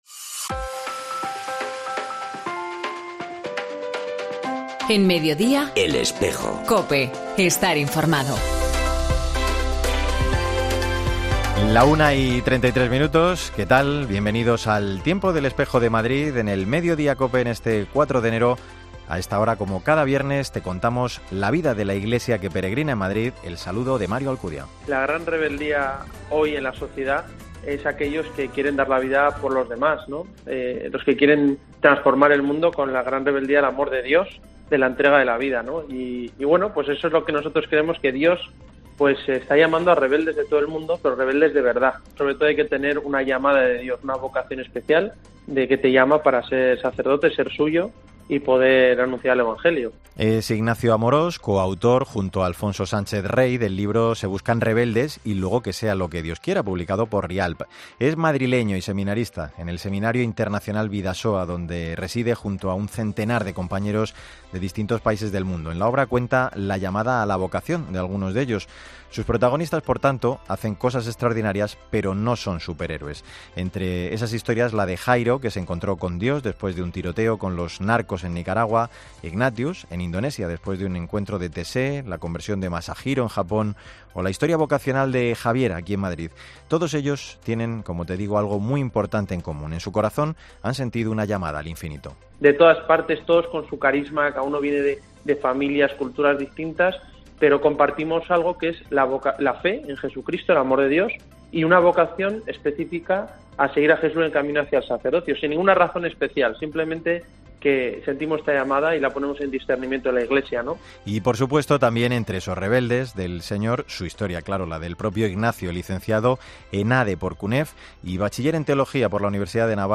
Jornada Paz en la Catedral,encuentro Espíritu de Asís en septiembre;entrevista